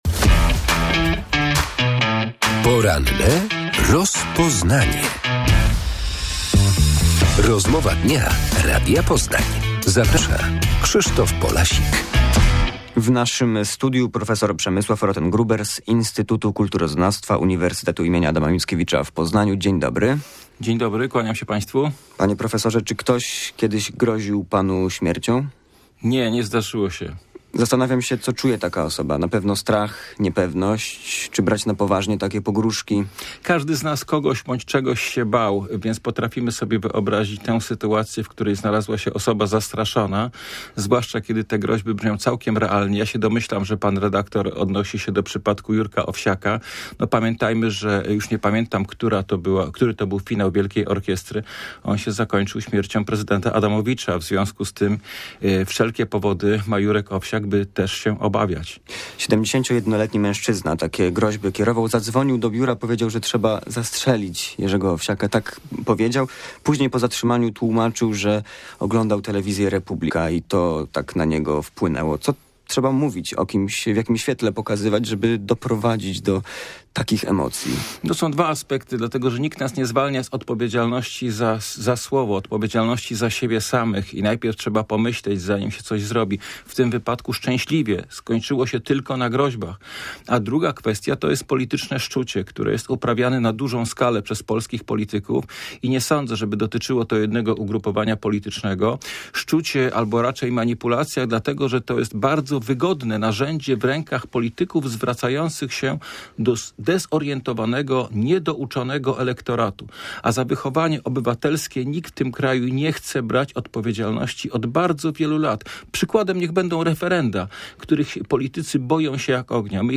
71-letni mężczyzna groził śmiercią Jerzemu Owsiakowi pod wpływem programu w telewizji Republika. O hejcie i polaryzacji polskiego społeczeństwa rozmawiamy z gościem porannej rozmowy